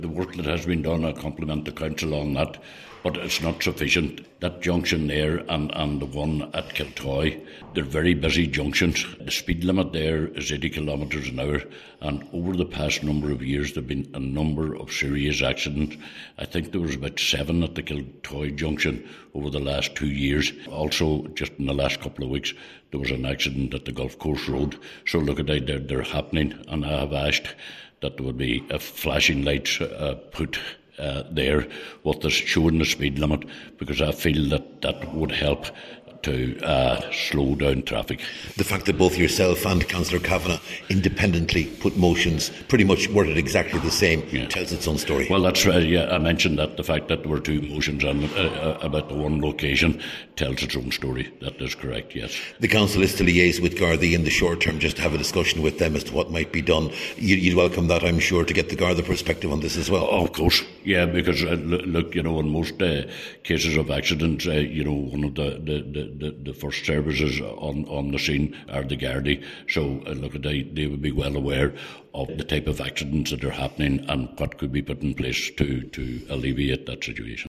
Cllr Donal Coyle says at the very least, electronic speed warning signs should be provided…..